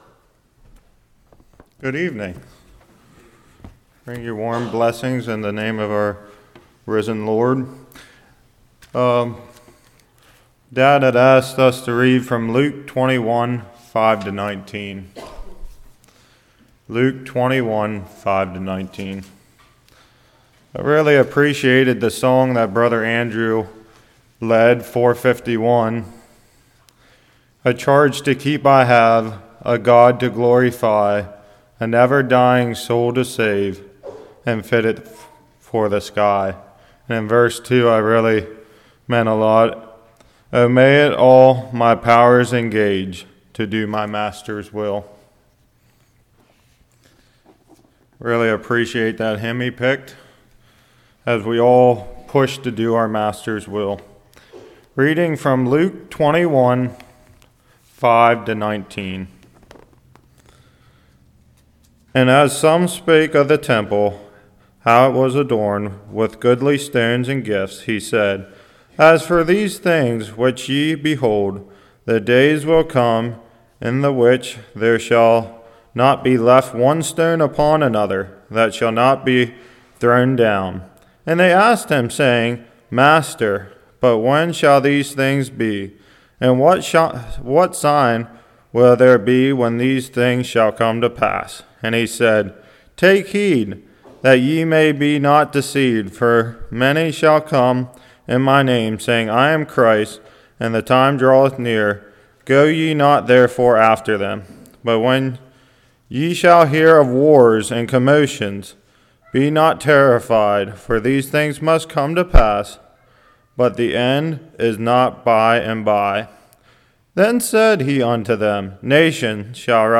Luke 21:5-19 Service Type: Evening When Shall These Things Be?